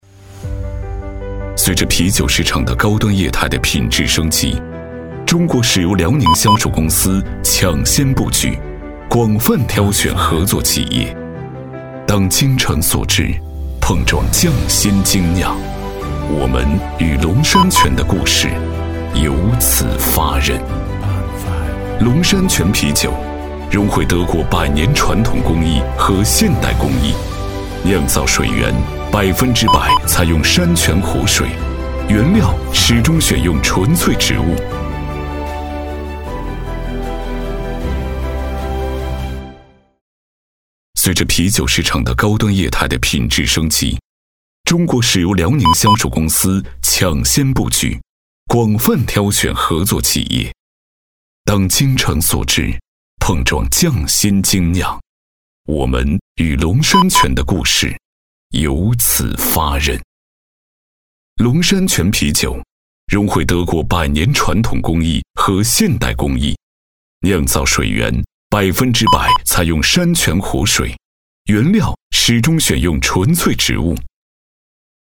190男-沧桑大气
特点：大气浑厚 稳重磁性 激情力度 成熟厚重
形象片——【大气质感】龙山泉啤酒.mp3